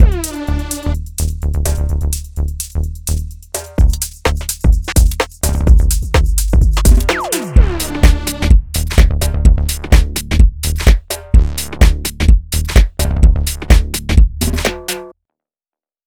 לא יודע למה אבל הבאס מפריע לי הוא מידיי חד